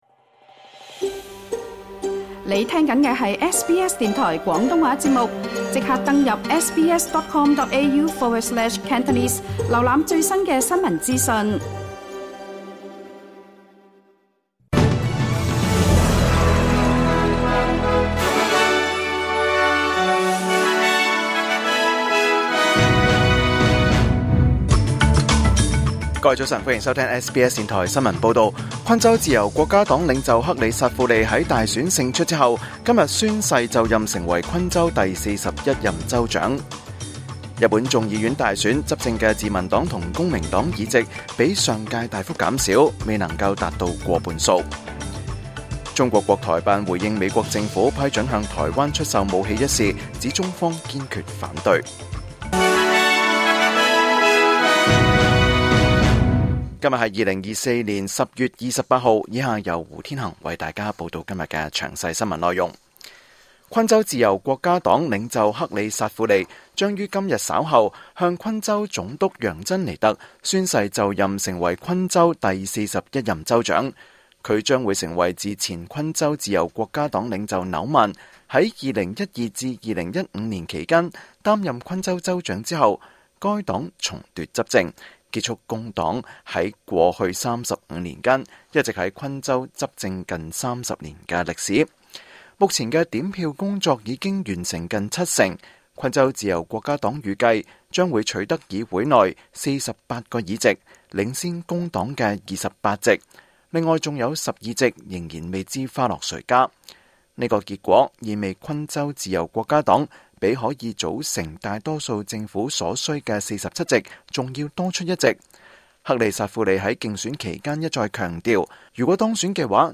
2024 年 10 月 28 日 SBS 廣東話節目詳盡早晨新聞報道。